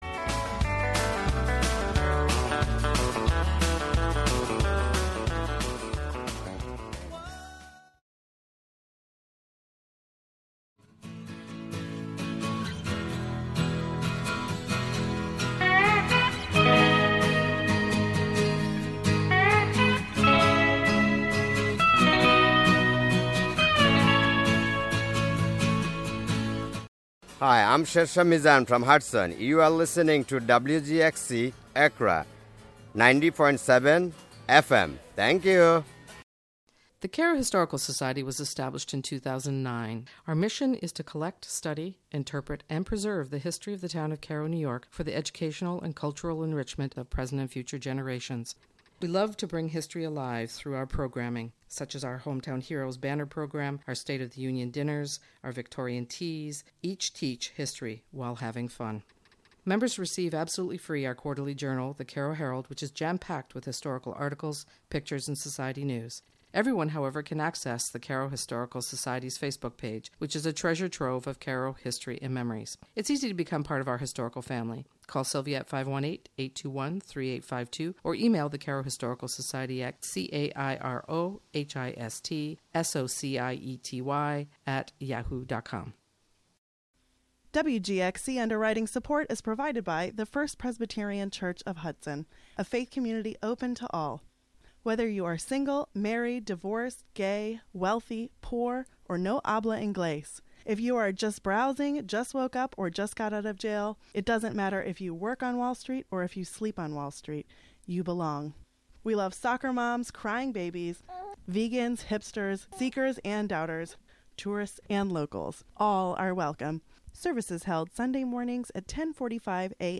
"All Together Now!" is a daily news show brought to you by WGXC-FM in Greene and Columbia counties. The show is a unique, community-based collaboration between listeners and programmers, both on-air and off. "All Together Now!" features local and regional news, weather updates, feature segments, and newsmaker interviews.